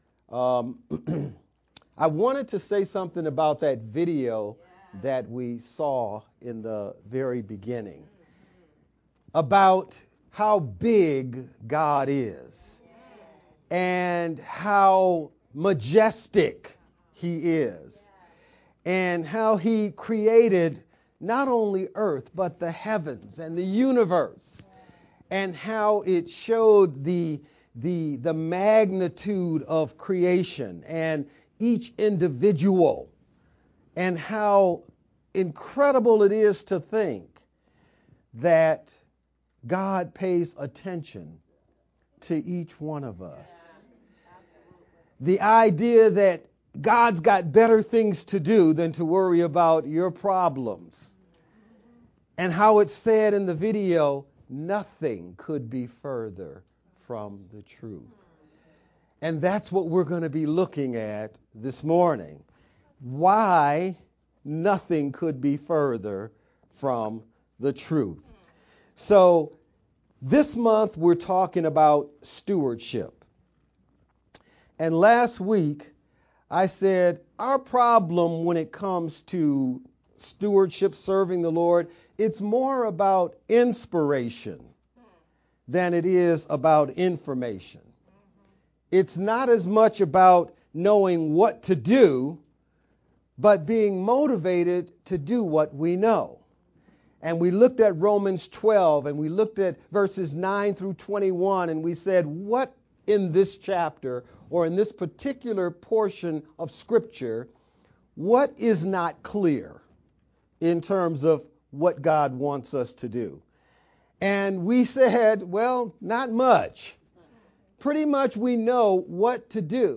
VBCC-Feb-11th-Sermon-only_Converted-CD.mp3